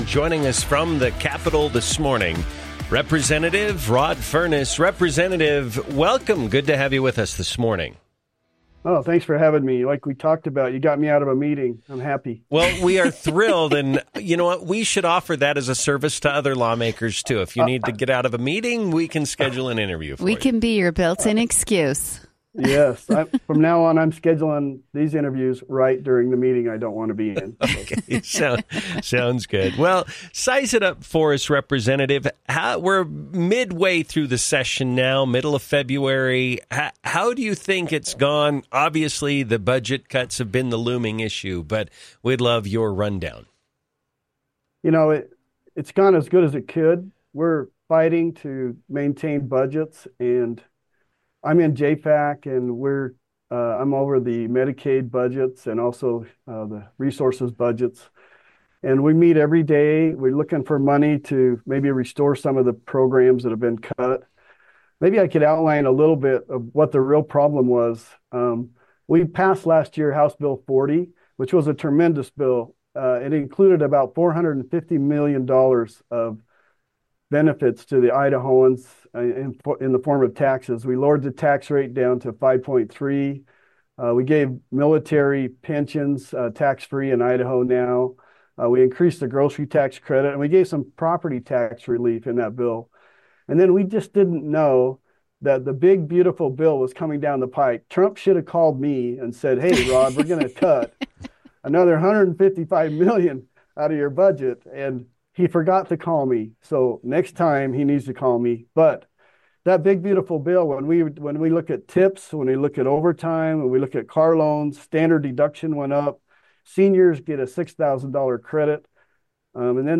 INTERVIEW: Rep. Rod Furniss on Budget Challenges, Re-election Bid - Newstalk 107.9